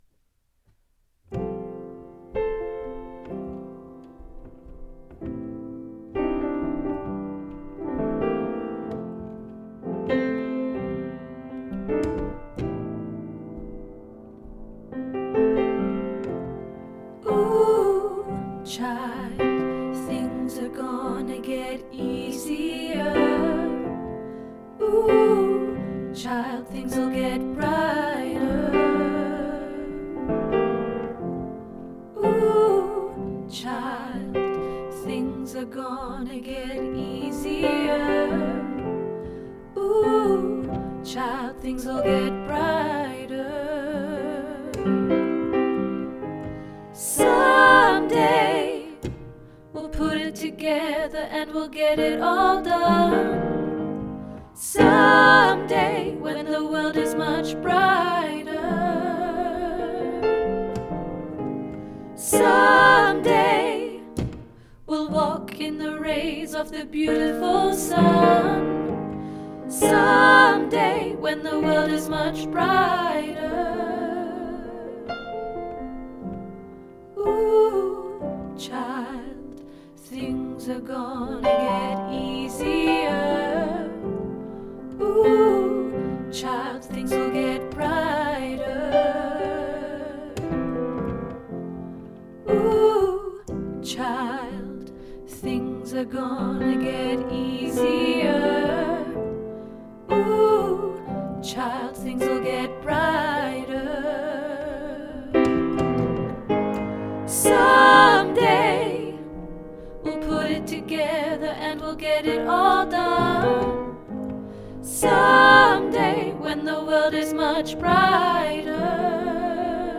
Ooh Child SATB